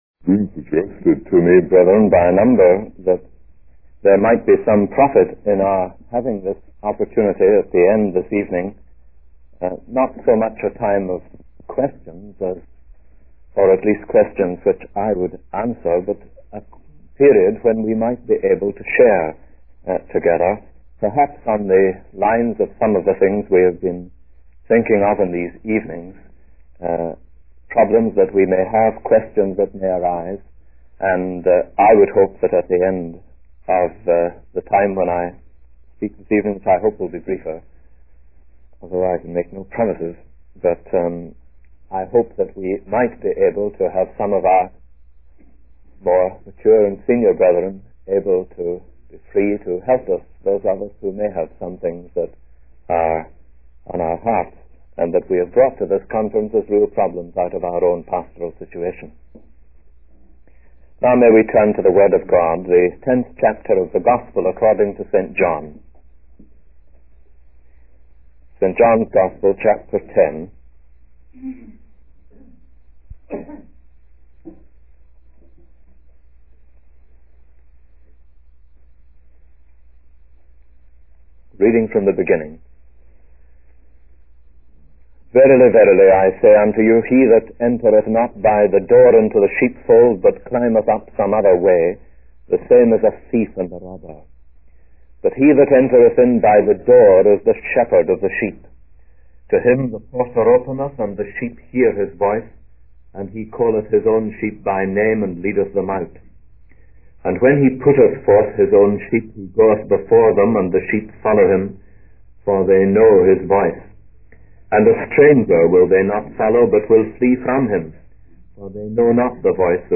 In this sermon, the speaker emphasizes the importance of being a man of conviction while also being gentle and loving towards those who disagree.